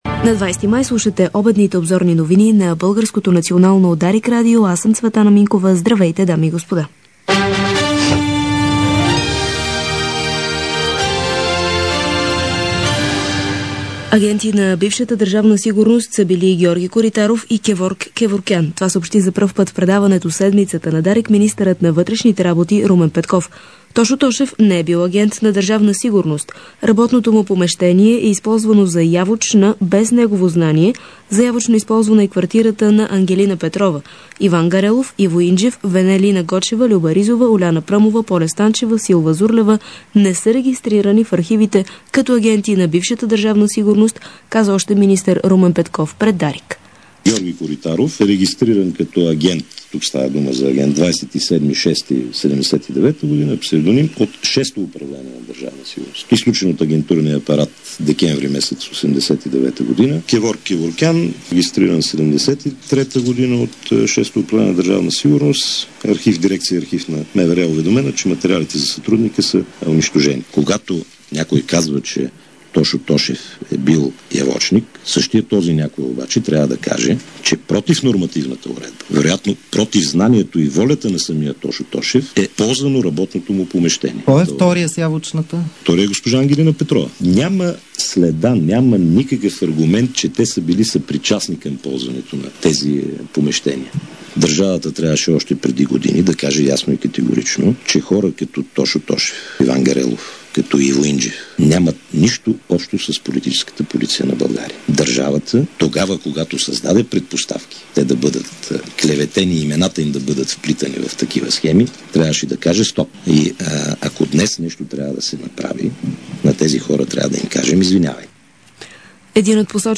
DarikNews audio: Обедна информационна емисия – 20.05.2006